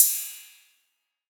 808CY_8_Tape_ST.wav